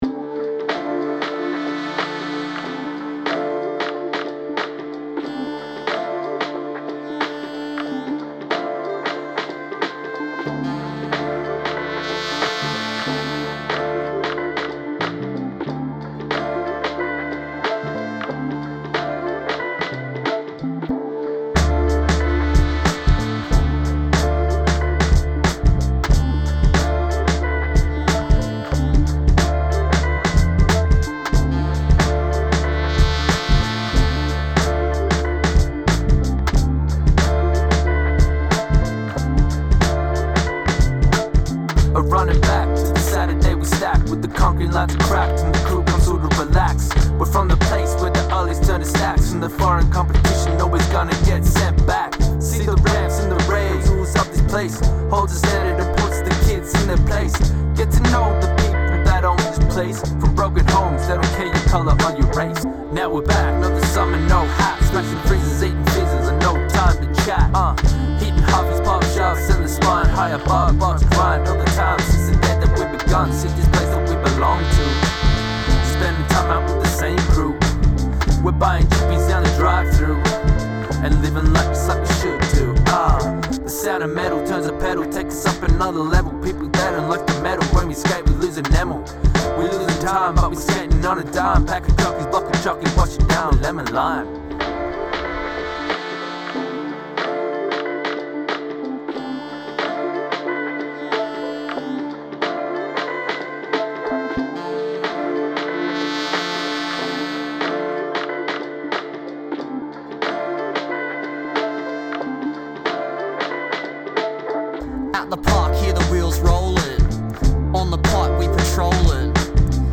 Group of young people singing into a microphone in a b & w ohoto
The journey culminated in four professionally recorded tracks, which were presented alongside corresponding artworks at the Place of Plenty Showcase.